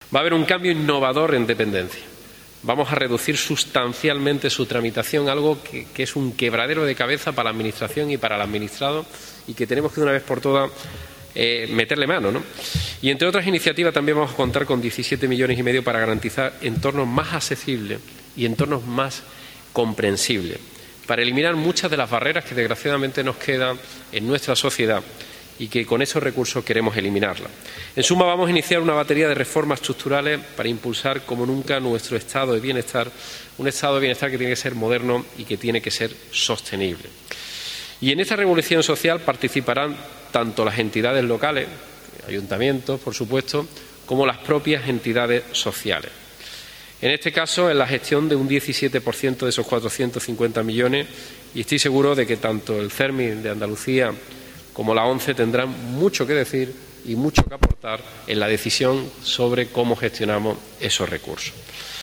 En el transcurso del mismo, el presidente de la Junta, Moreno Bonilla, abogó por incorporar la discapacidad en la agenda política y aseguró que el Gobierno andaluz está plenamente implicado en dar pasos hacia la igualdad efectiva.
CORTEJuanmaMorenoBonilla.mp3